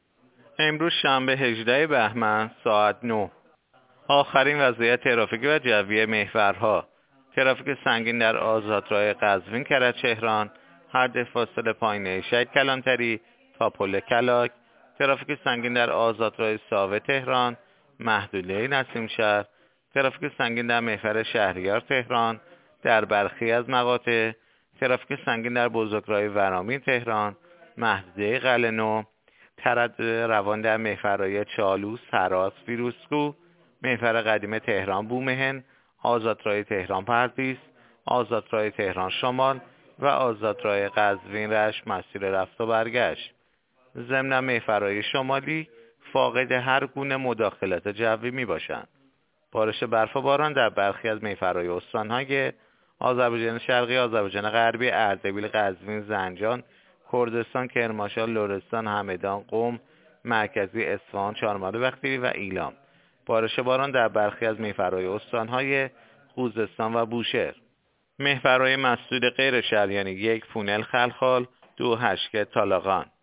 گزارش رادیو اینترنتی از آخرین وضعیت ترافیکی جاده‌ها ساعت ۹ ششم اسفند؛